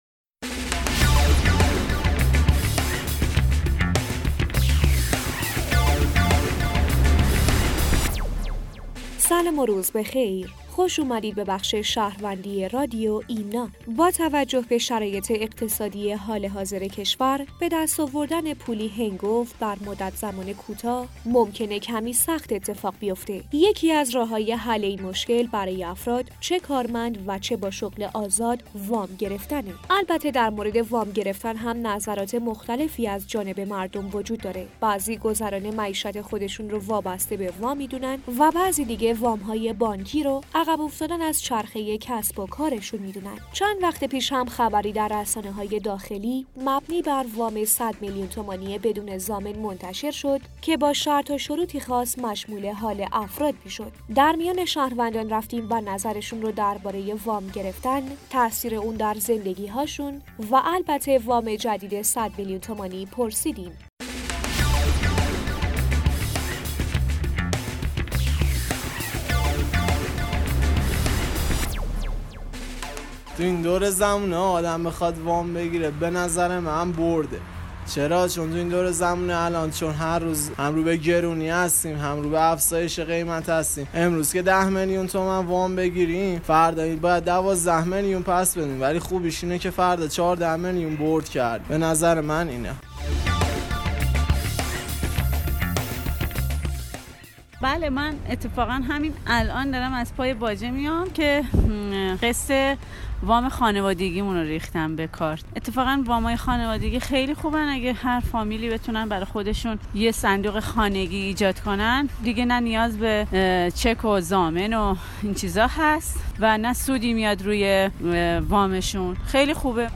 در این قسمت از رادیو شهروندی ایمنا، شهروندان از تاثیر وام گرفتن در زندگی هایشان، مزایا و معایب آن و همچنین وام جدید ۱۰۰ میلیون تومانی بدون ضامن می گویند.